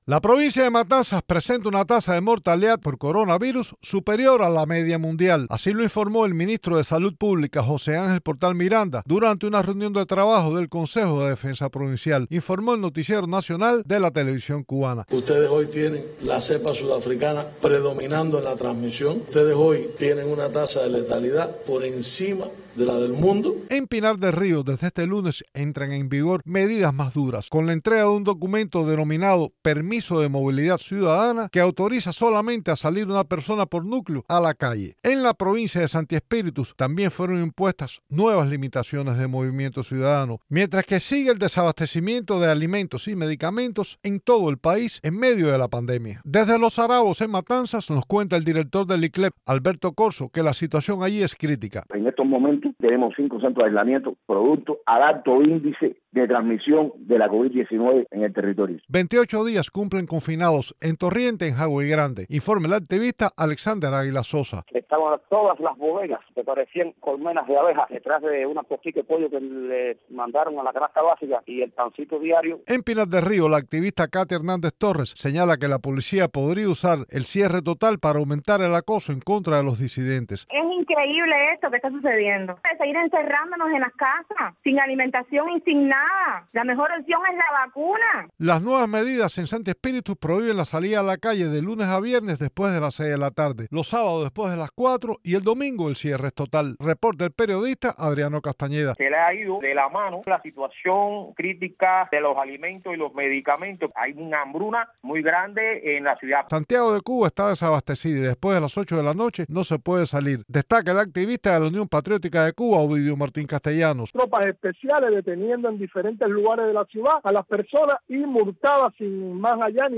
Reportaje